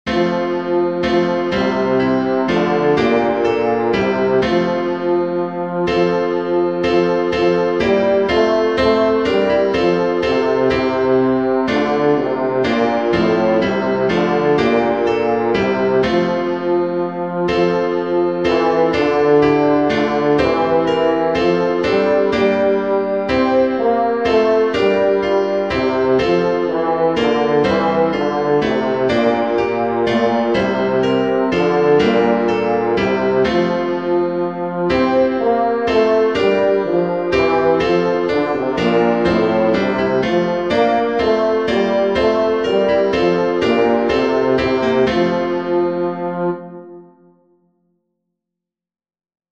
Bass
“Holy God, We Praise Thy Name” (original German: “Großer Gott, wir loben dich”) is an ecumenical hymn.
grosser_gott_holy_god_we_praise-bass.mp3